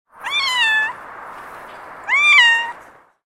Cute Cat Meow Sound Effect
Description: Cute cat meow sound effect.
Cat meowing, mewing, miaow, miaowing. Animal sounds.
Cute-cat-meow-sound-effect.mp3